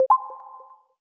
click-short-confirm.wav